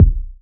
• Dope Urban Kick Drum Single Hit C Key 72.wav
Royality free kick drum sample tuned to the C note. Loudest frequency: 107Hz
dope-urban-kick-drum-single-hit-c-key-72-mxm.wav